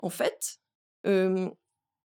VO_ALL_Interjection_12.ogg